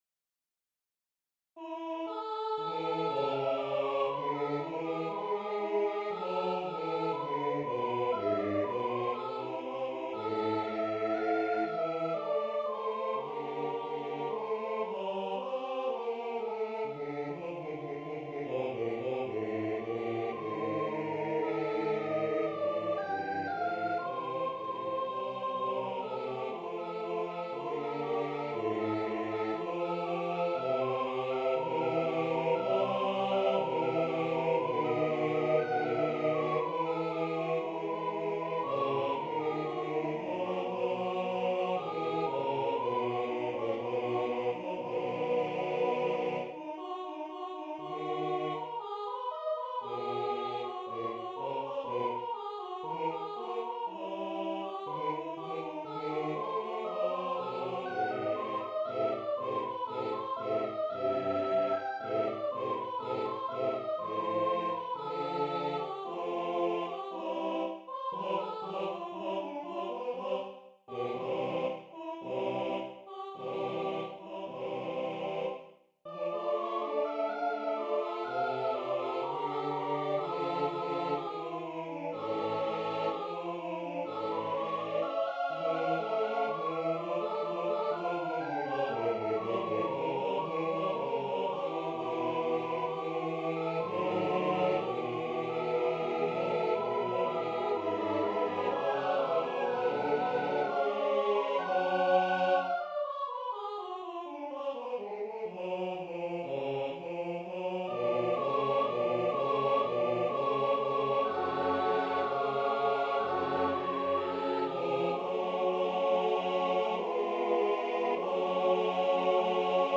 A piece for choir